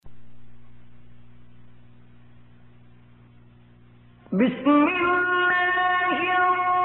Holy Qur'an Majid